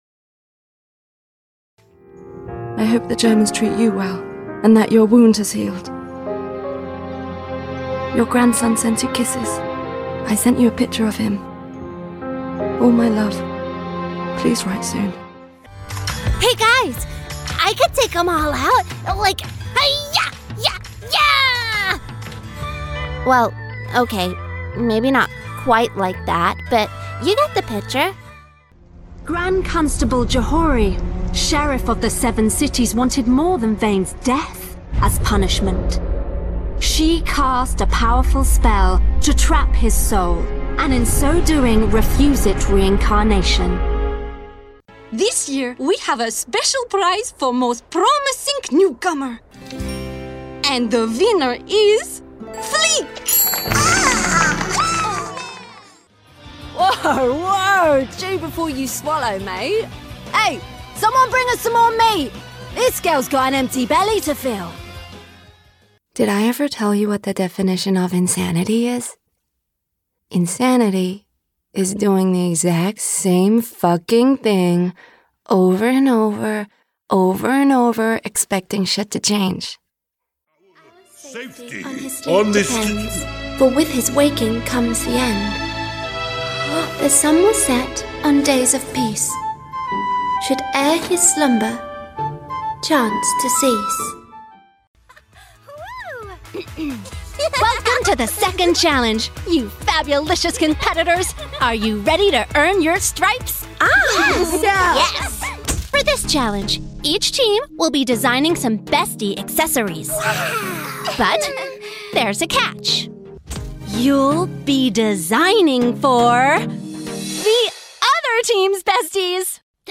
Character Showreel
Female
London
Neutral British
Bright
Friendly
Soft
Warm
Youthful